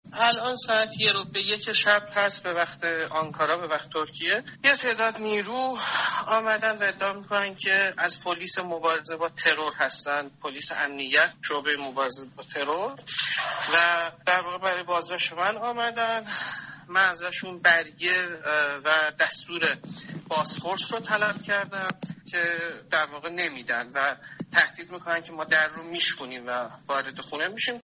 گفت‌وگوی کوتاهی با رادیو فردا